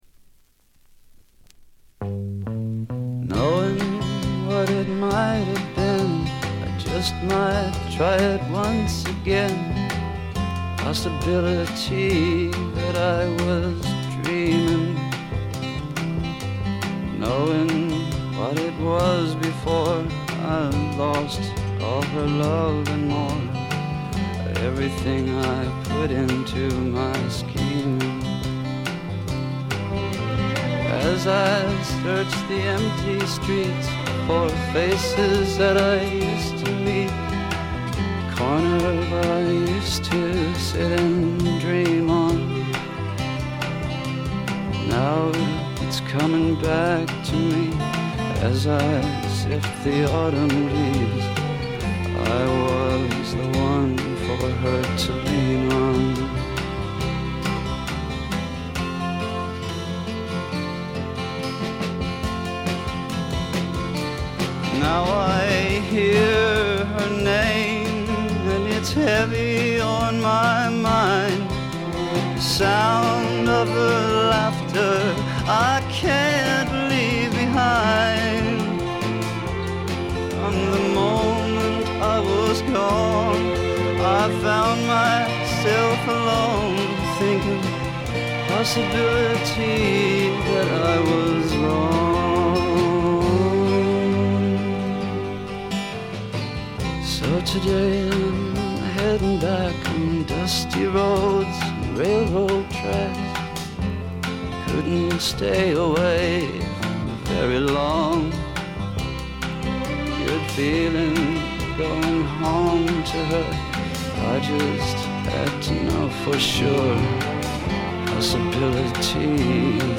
ところどころでチリプチ。
すべて自作曲で独特のヴォーカルもしっかりとした存在感があります。
試聴曲は現品からの取り込み音源です。